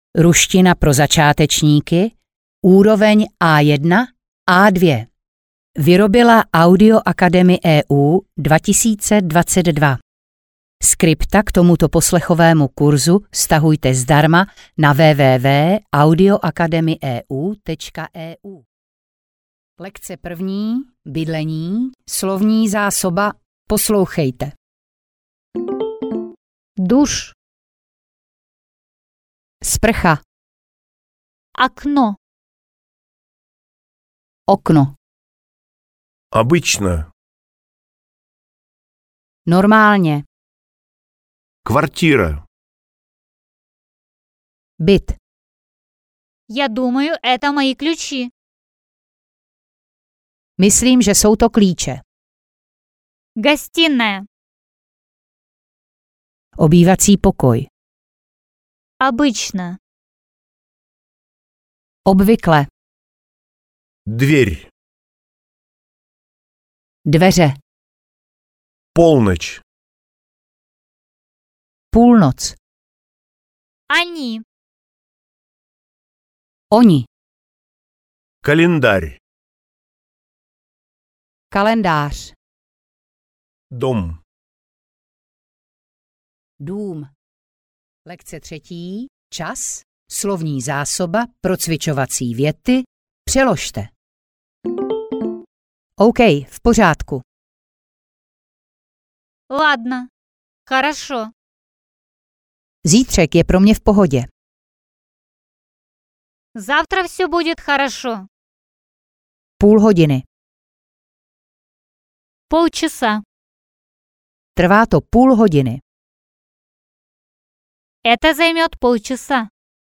Audiokniha Ruština pro začátečníky A1-A2.
Jakmile budete zvládat překládat věty z ruštiny do češtiny (lekce 6) v časové pauze před českým překladem, tak jste vyhráli.